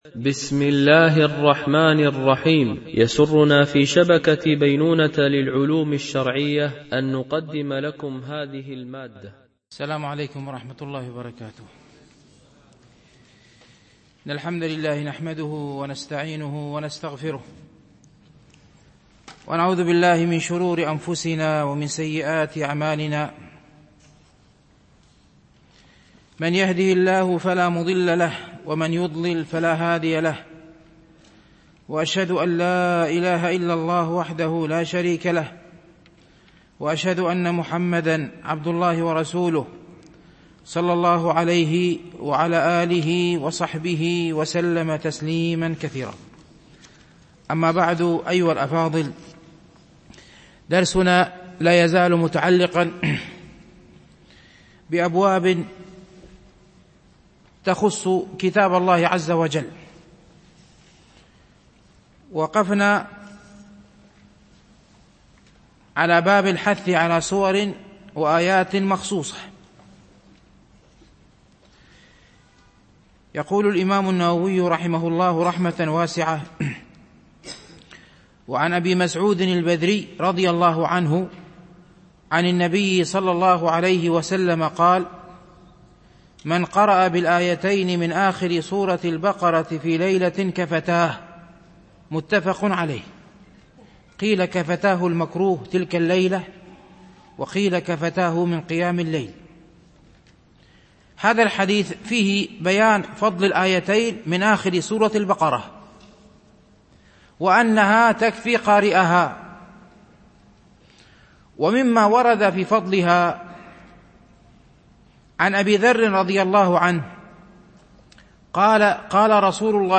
شرح رياض الصالحين – الدرس 265 ( الحديث 1024 – 1026 )
MP3 Mono 22kHz 32Kbps (CBR)